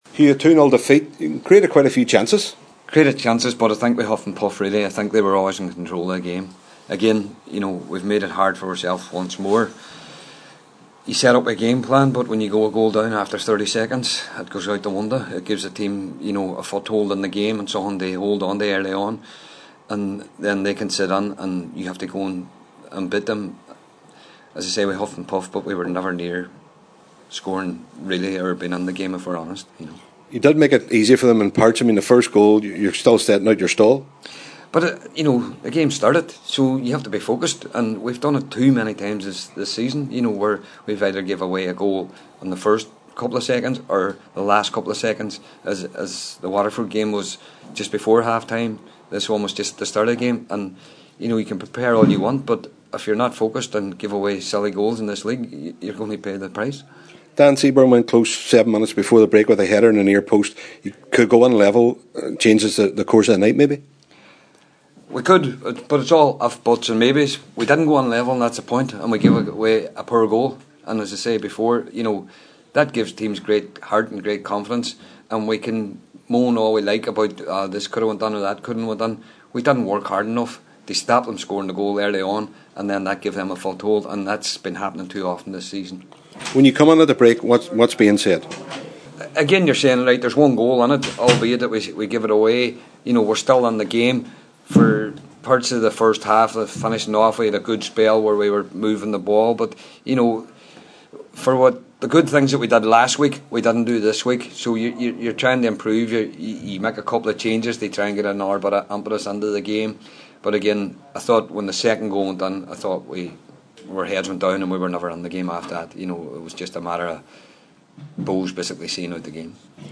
After the match